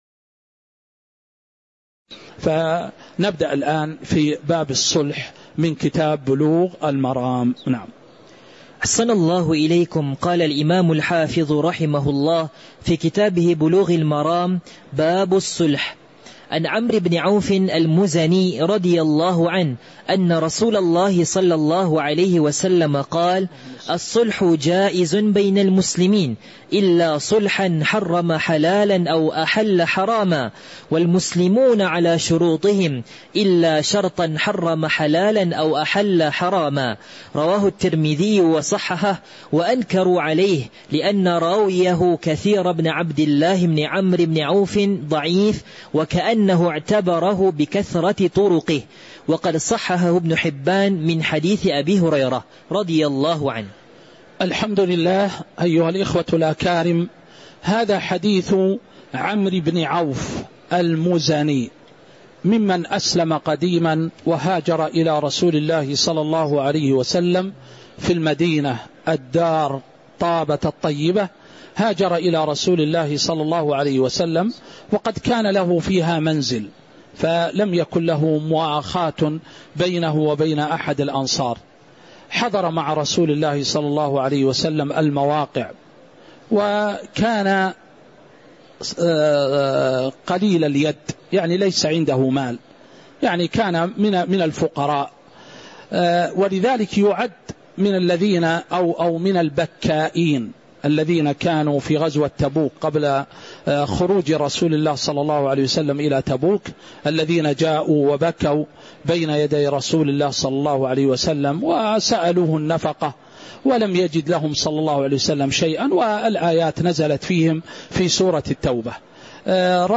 تاريخ النشر ١٥ جمادى الأولى ١٤٤٦ هـ المكان: المسجد النبوي الشيخ